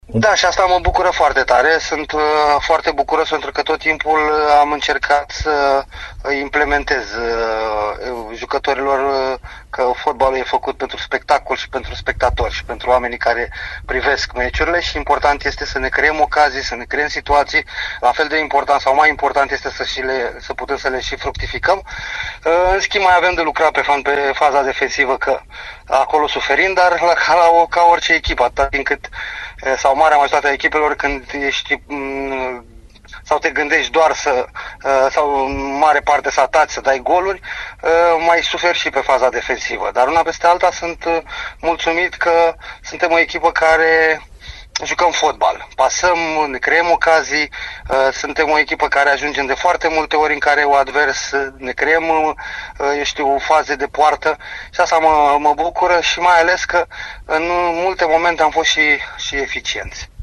Antrenorul Flavius Stoican a analizat, la Radio Timișoara, parcursul din actuala stagiune a echipei sale.